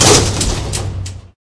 elevator_stop.ogg